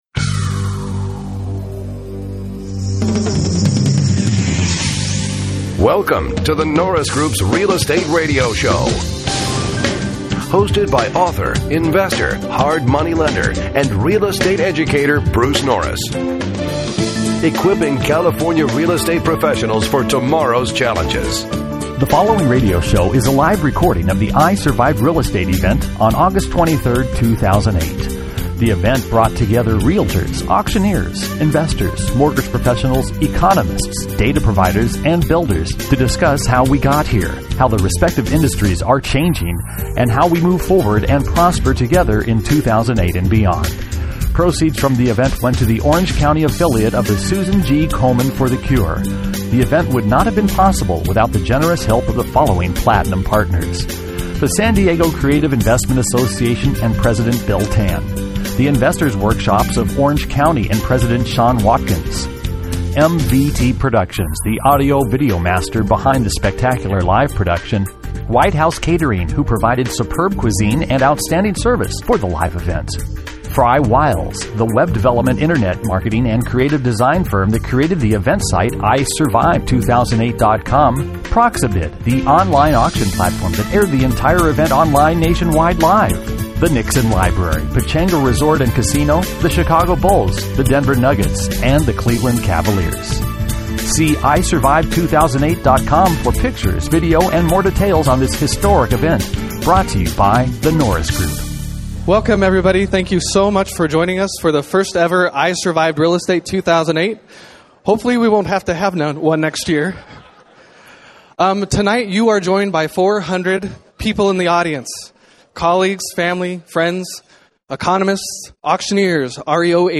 Eight industry experts from different real estate sectors converged to discuss how we got here, where we're going, and how we move forward together and prosper.